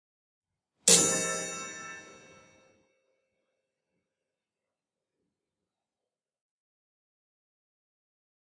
Clockworth " 钟声罢工，变体2
Some were intentionally muted with my fingers while striking.
Intended for organic nonsampleidentical repetition like when a real clock strikes the hour.
Tag: 祖父时钟 报时 时间 发条 祖父 磬杆 时钟 小时 风铃